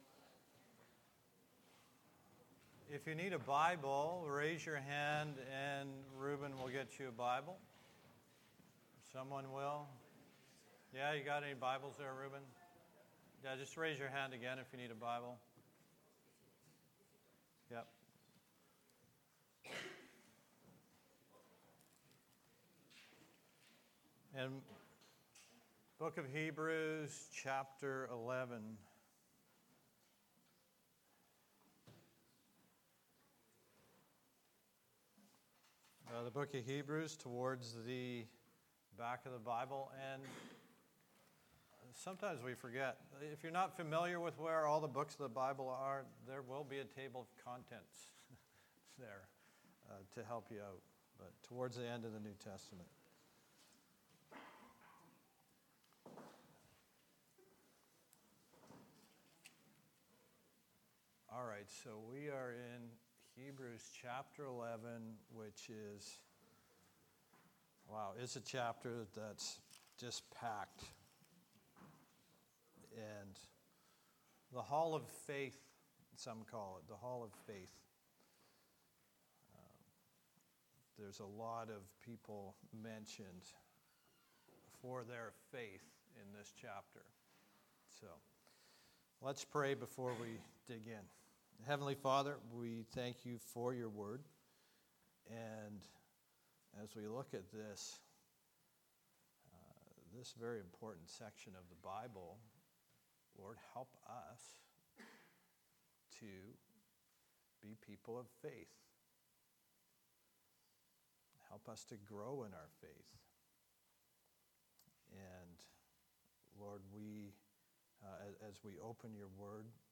Sermons | Calvary Chapel Comox Valley